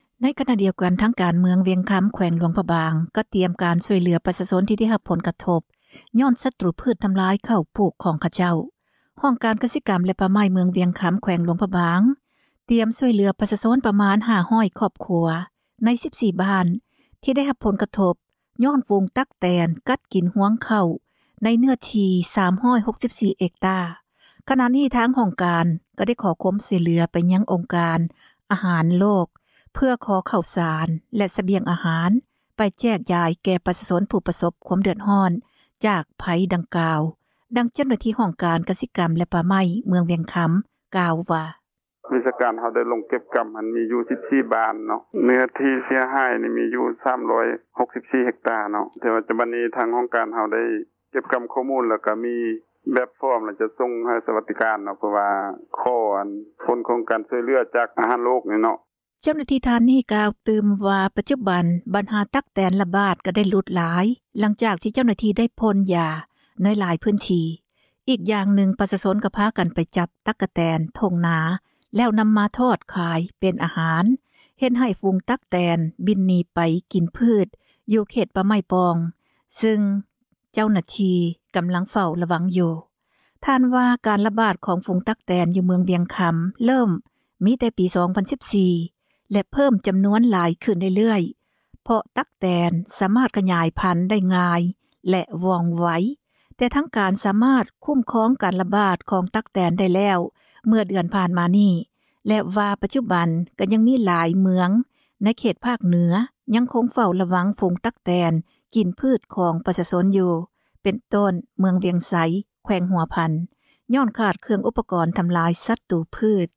ມ.ວຽງຄໍາຕຽມຂໍເຂົ້າສານ ຊ່ອຍ ຊາວບ້ານ — ຂ່າວລາວ ວິທຍຸເອເຊັຽເສຣີ ພາສາລາວ